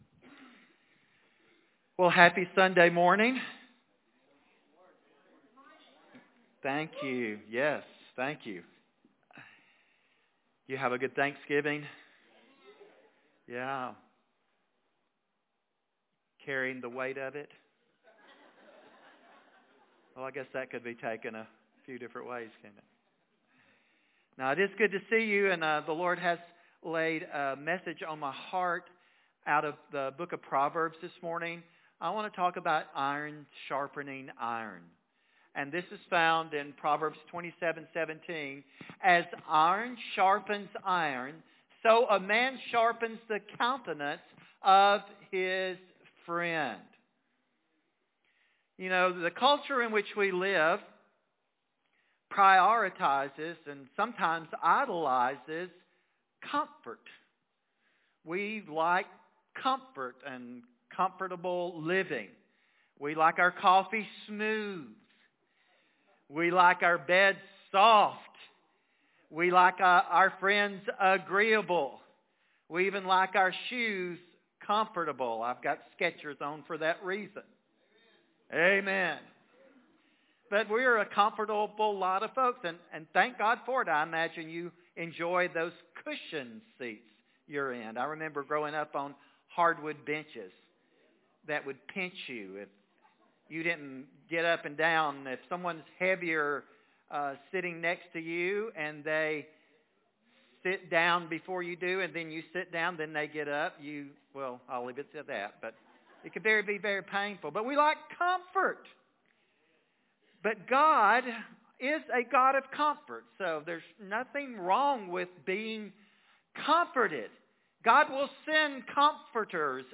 Podcast message